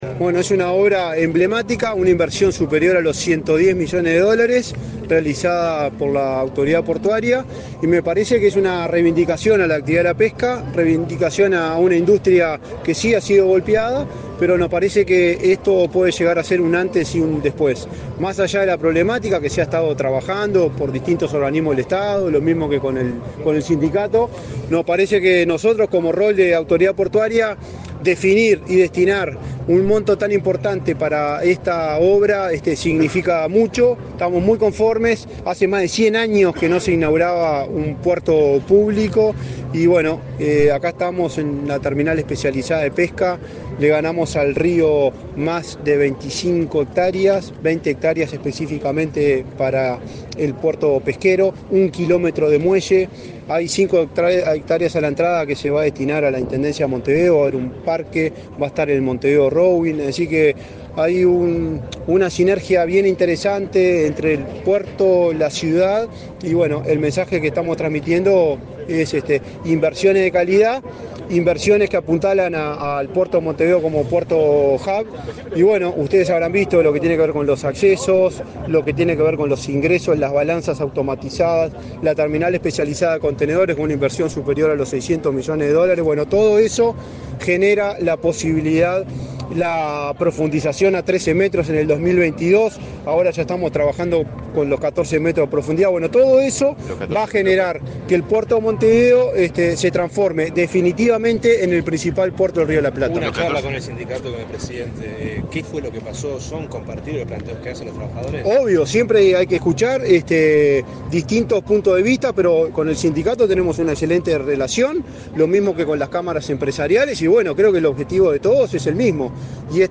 Declaraciones a la prensa del presidente de la ANP, Juan Curbelo
Declaraciones a la prensa del presidente de la ANP, Juan Curbelo 04/09/2024 Compartir Facebook X Copiar enlace WhatsApp LinkedIn El presidente de la República, Luis Lacalle Pou, participó en el acto de inauguración del puerto Capurro, en Montevideo, este 3 de setiembre. Tras el evento, el presidente de la Administración Nacional de Puertos (ANP), Juan Curbelo, realizó declaraciones a la prensa.